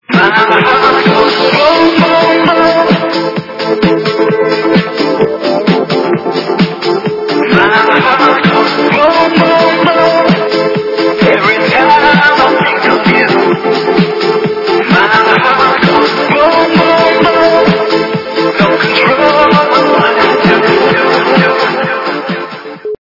- Remix
При заказе вы получаете реалтон без искажений.